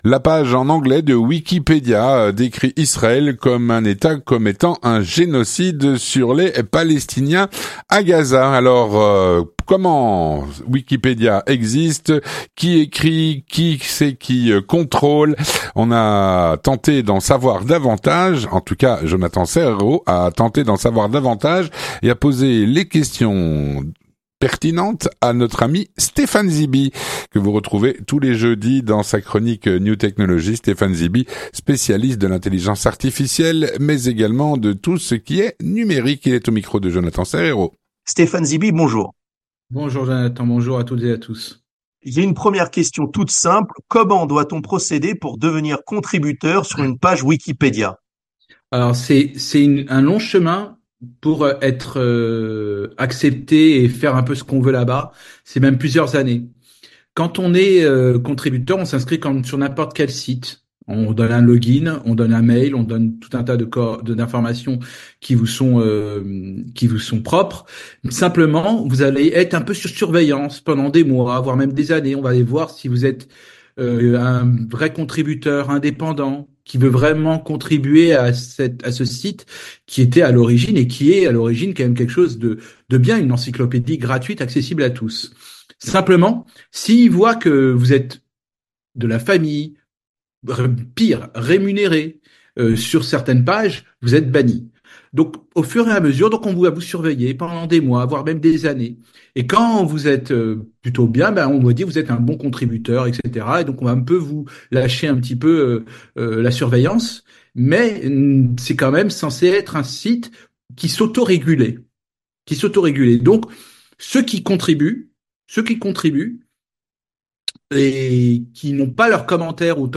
L'entretien du 18H - Sur la page "Wikipedia" en anglais, il est renseigné qu'Israël commet un génocide contre les palestiniens.